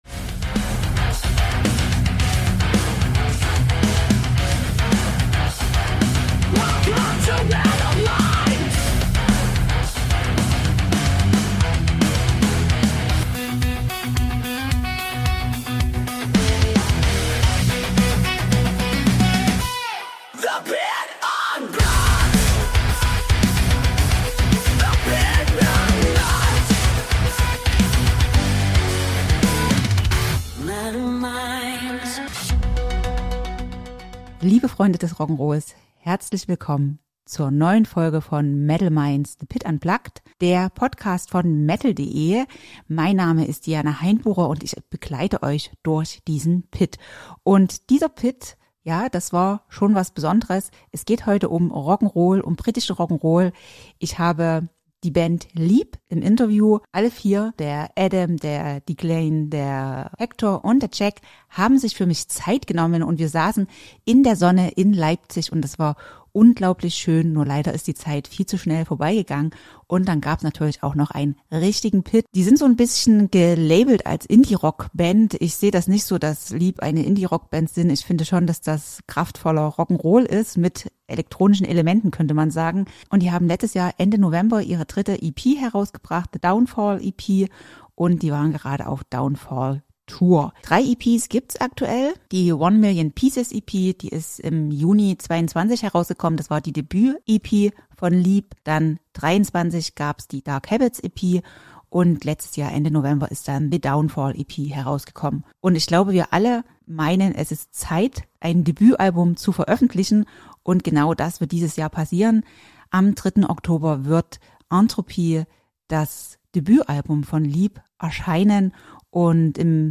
Ein Gespräch über Mut, Musik – und das, was uns bewegt.